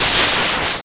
Monster2.wav